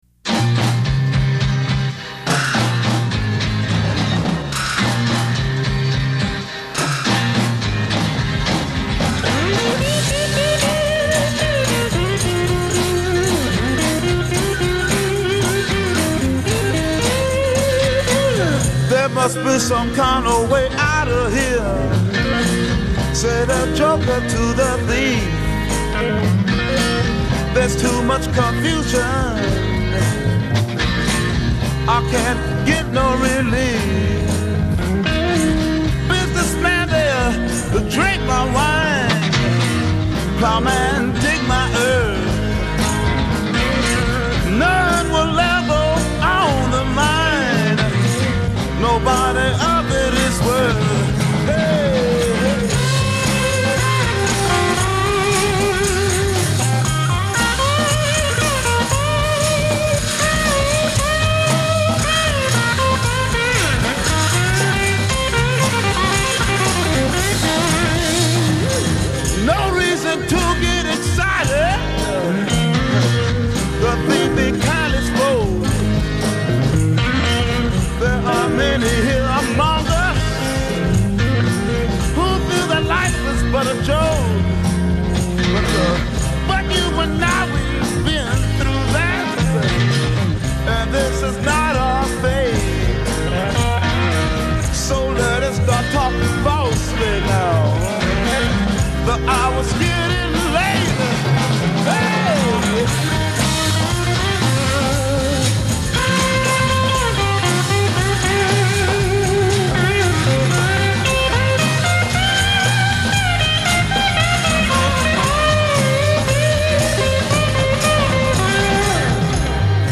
vocal, electric guitars, and bass guitar
acoustic guitar
drums
Introduction   Guitars over bass and drums
Verse   Guitar solo (with slides and echo)
Verse   Guitar solo (with wah wah)
Verse   Guitar solos (repeat and fade)
Transatlantic Psychedelic Blues